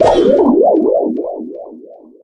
bibi_bubble_gadget_01.ogg